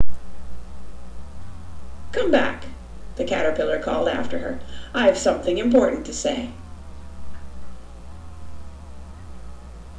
在以下所有录音中、我将麦克风放置在距离显示器约6英寸的位置。
与我之前的一些录音一样、您可以听到"数字"噪声的存在。